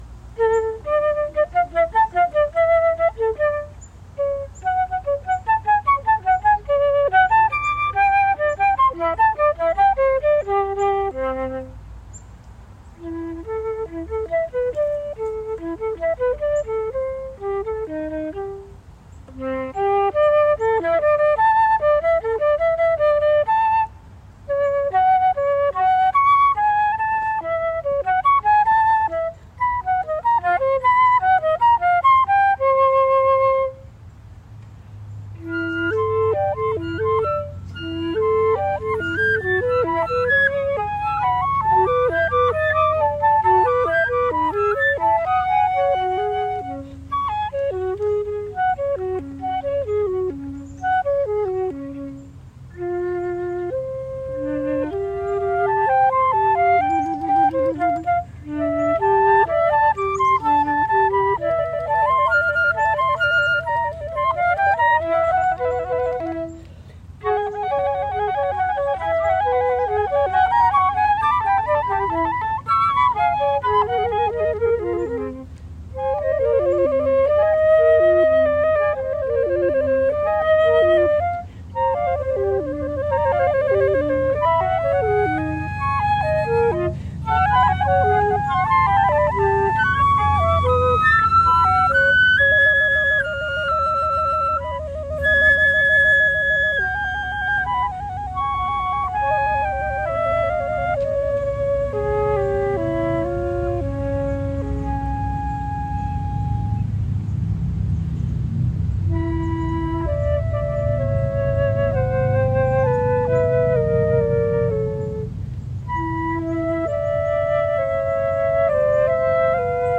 Flute duets performed live at Burris Park in Marine on St. Croix